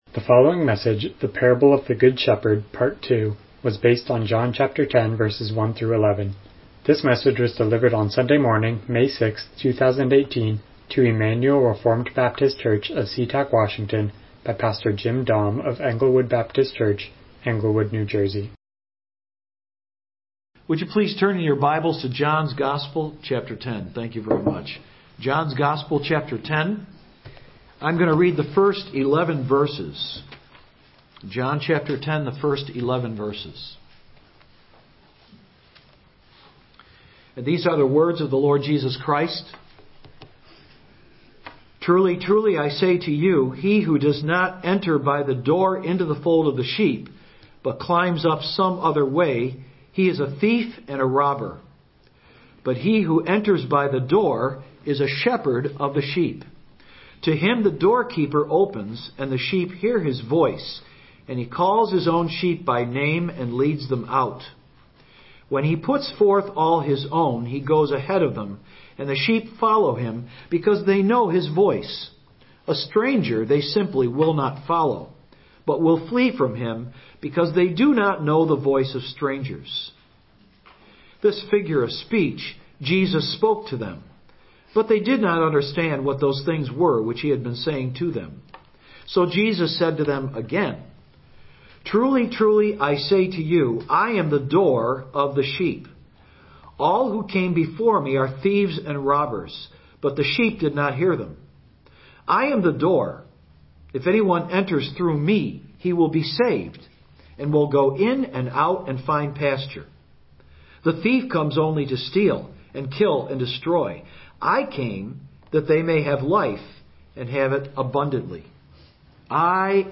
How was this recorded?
Passage: John 10:1-11 Service Type: Morning Worship « “He who has ears to hear